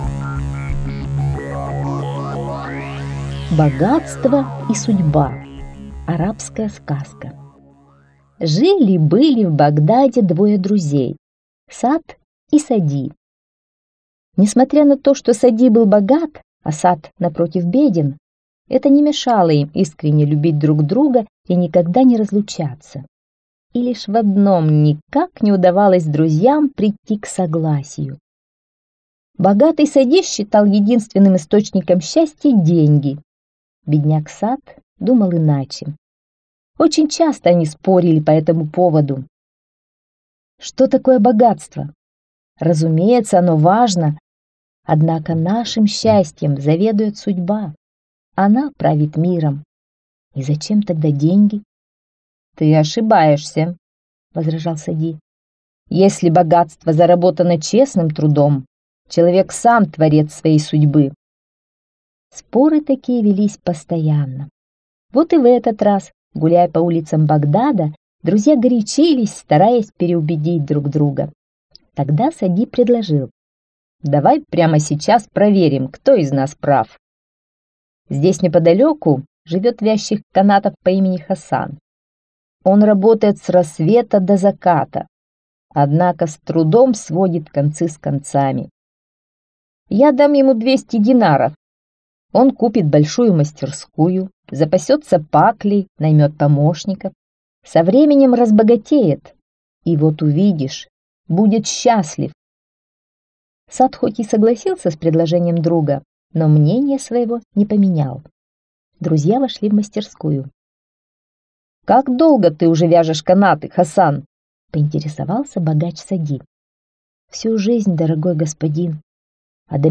Богатство и судьба - арабская аудиосказка. Сказка про двух друзей - богатого и бедного. Они часто спорили, что является источником счастья...